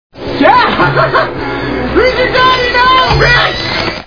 Snakes on a Plane Movie Sound Bites